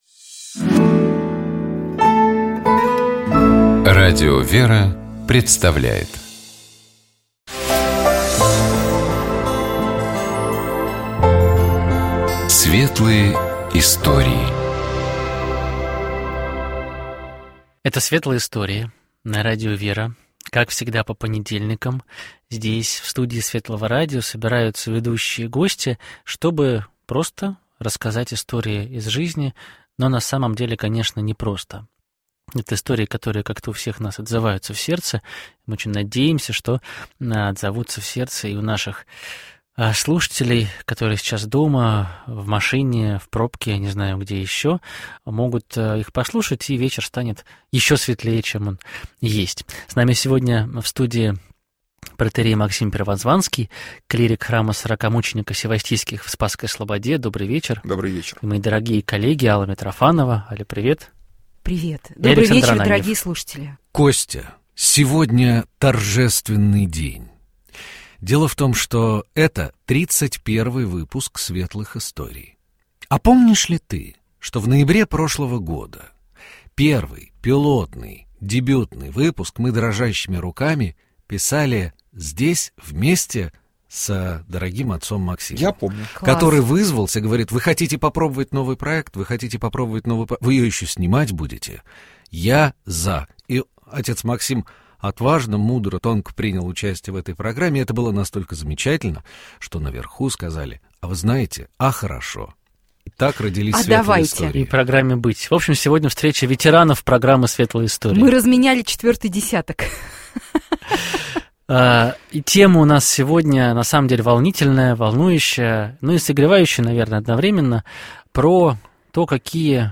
В нашей студии